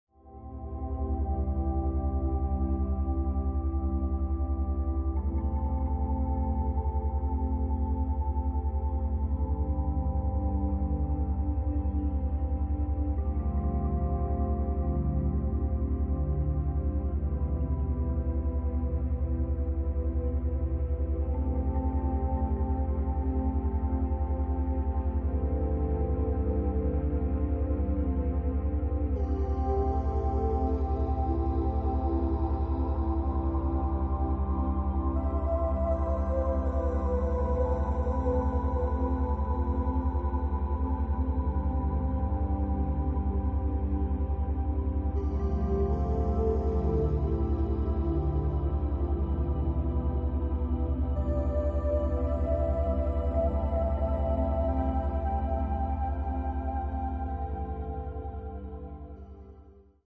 Try listening to Mp3 Sound Effect Trouble sleeping? Try listening to the sound of soothing binaural beats in the Alpha frequency before bed tonight.